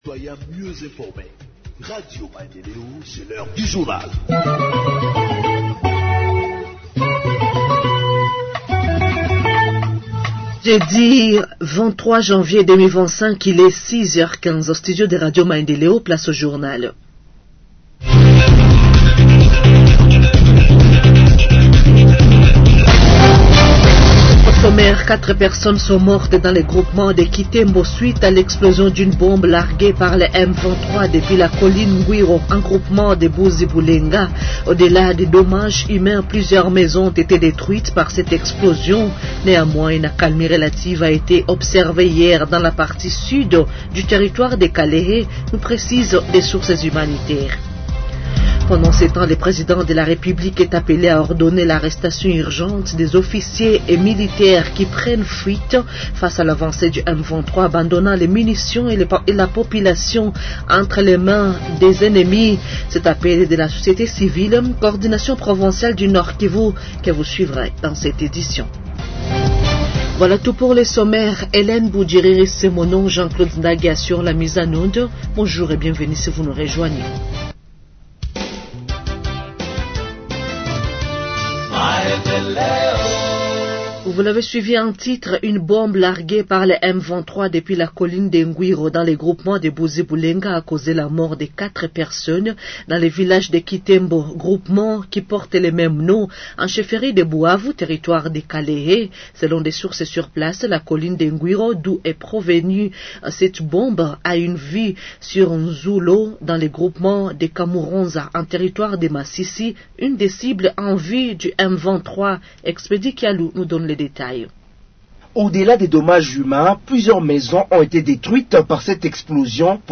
Journal en Français du 23 Janvier 2025 – Radio Maendeleo